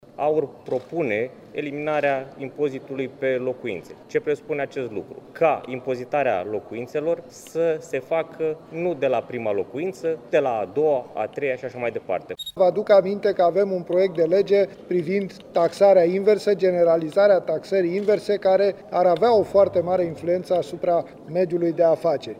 Deputatul AUR, Alin Avrămescu: „Impozitarea locuințelor să se facă nu de la prima locuință, ci de la a doua, a treia și așa mai departe”
Parlamentarul AUR, Petrișor Peiu: „Ar avea o mare influență asupra mediului de afaceri”
02feb-15-Avramescu-si-Peiu-fara-impozit-daca-ai-doar-o-casa.mp3